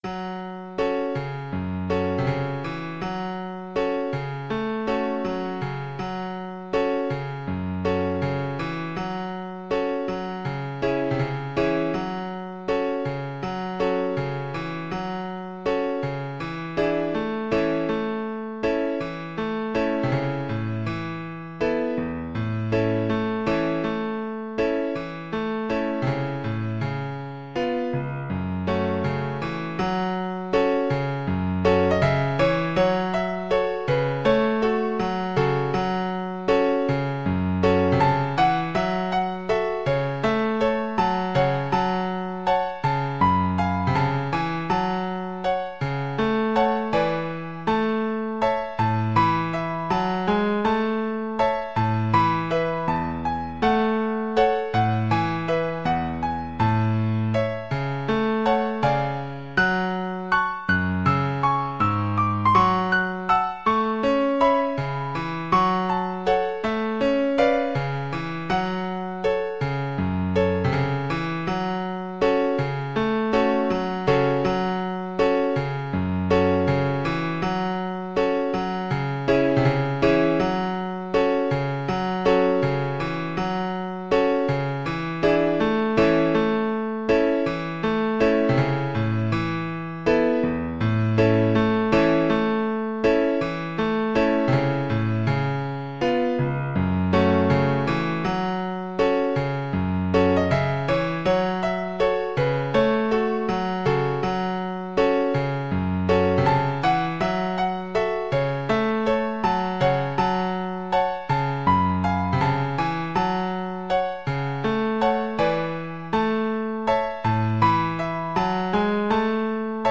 Allegro e cantabile = c. 128 (View more music marked Allegro)
4/4 (View more 4/4 Music)
F# minor (Sounding Pitch) (View more F# minor Music for Violin )
Violin  (View more Intermediate Violin Music)